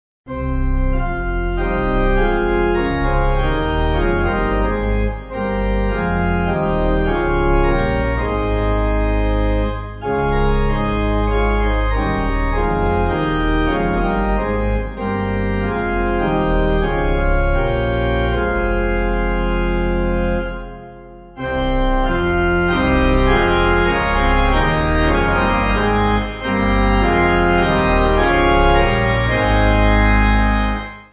Organ
(CM)   5/Fm